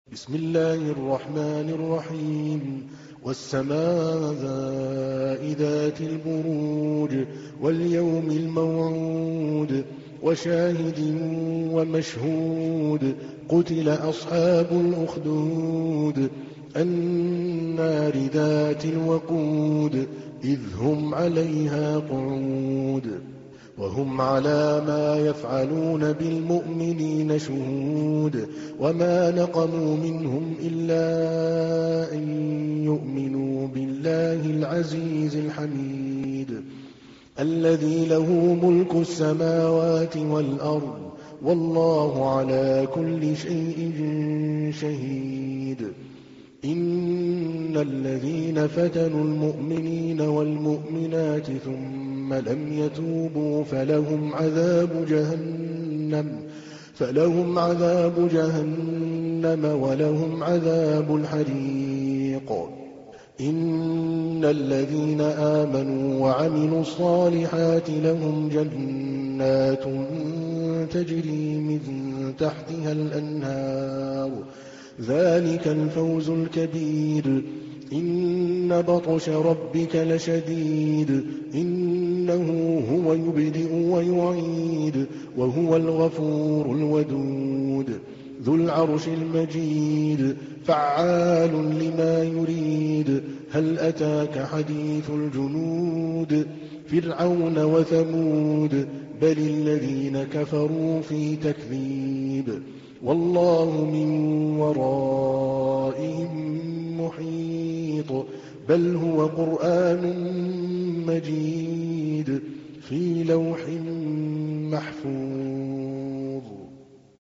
تحميل : 85. سورة البروج / القارئ عادل الكلباني / القرآن الكريم / موقع يا حسين